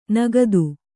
♪ nagadu